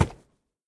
sounds / mob / horse / wood2.mp3
wood2.mp3